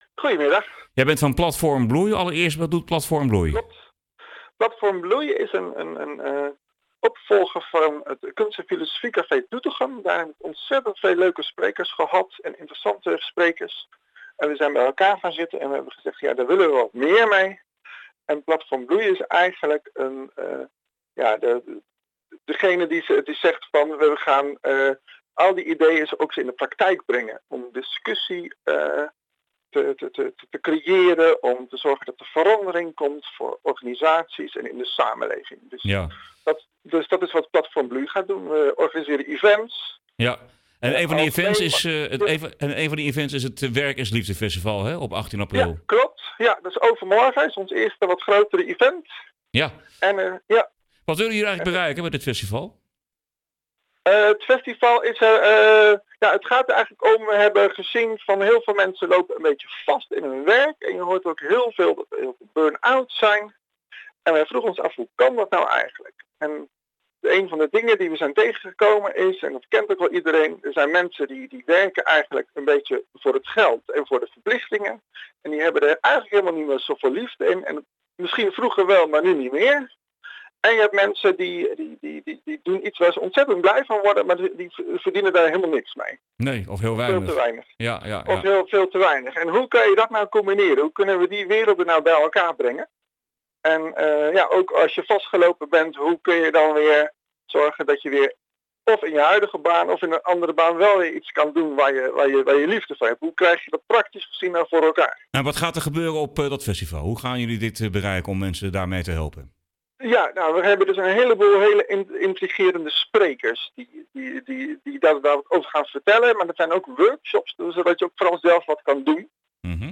Radio-interview voor Optimaal FM op 16 april 2019